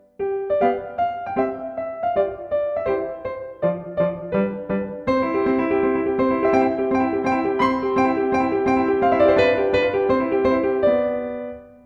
まずは軽快にハ長調で始まるハイドンのソナタ。右手の軽快な旋律に対比するように、左手の伴奏部、３連符が続きます。
冒頭から、まるで指慣らしのように軽快な左手の３連符が連呼します。